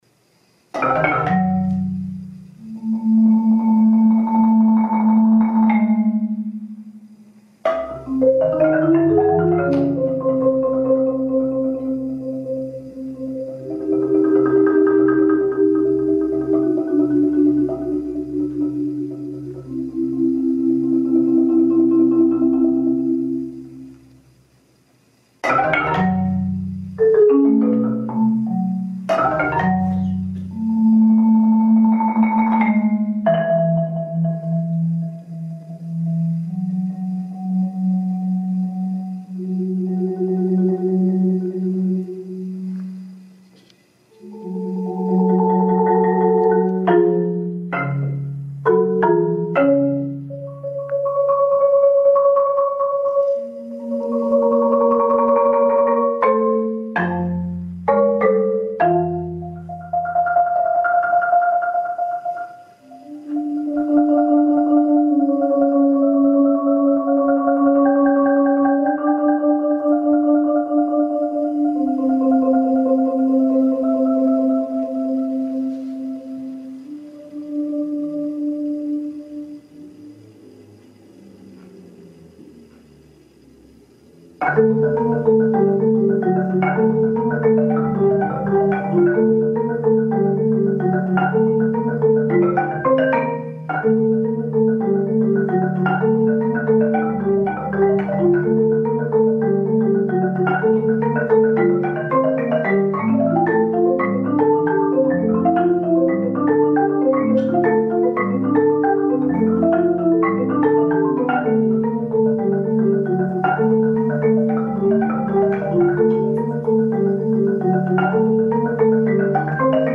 Genre: Marimba (4-mallet)
# of Players: 1
Marimba (5-octave)